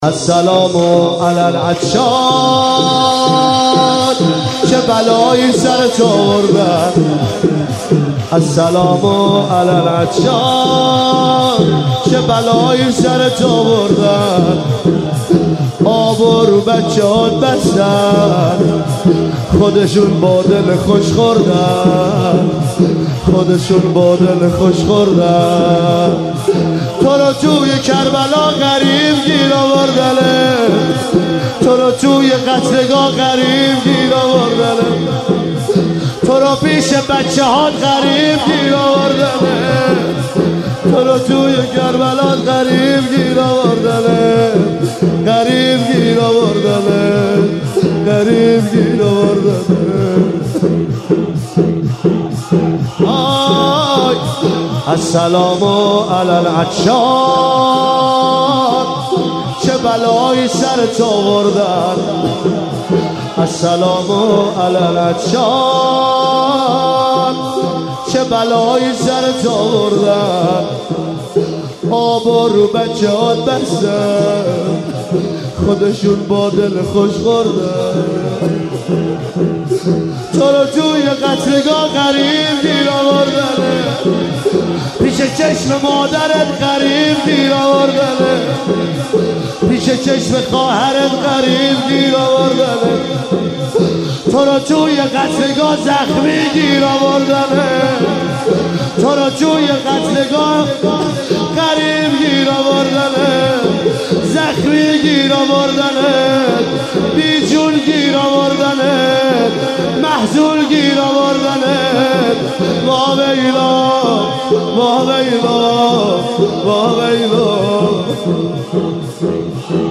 شب پنجم محرم 96 - شور - السلام علی العطشان